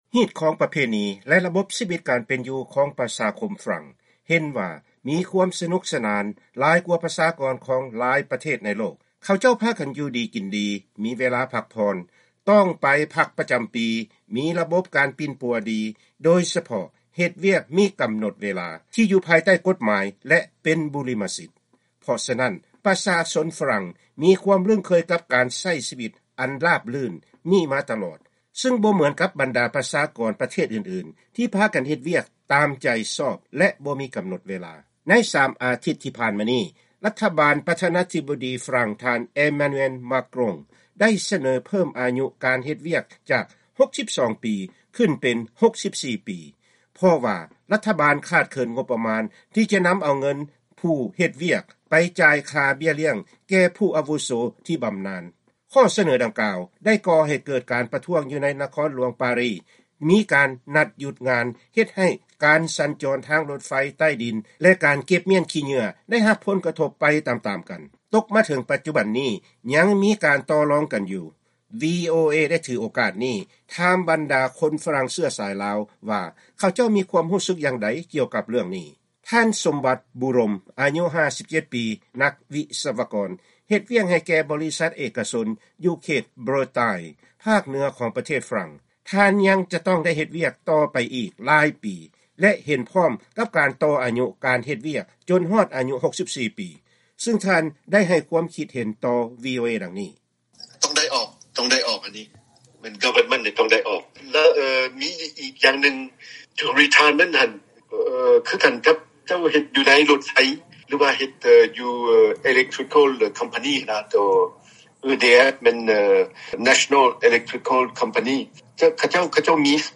ໃນລາຍການຊີວິດຊາວລາວຂອງ ວີໂອເອ ປະຈຳວັນພະຫັດມື້ນີ້ ເຮົາຈະພາທ່ານຟັງການໂອ້ລົມກັບຄົນຝຣັ່ງເຊື້ອສາຍລາວຢູ່ໃນເຂດນະຄອນຫລວງປາຣີ ກ່ຽວກັບວິກິດການລັດຖະບານຝຣັ່ງໄດ້ສະເໜີໃຫ້ຄົນພາກັນເຮັດວຽກຈົນຮອດ 64 ປີ ແທນທີ່ຈະເປັນ 62 ປີ ດັ່ງທີ່ເຄີຍປະຕິບັດກັນມາ.